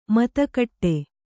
♪ mata kaṭṭe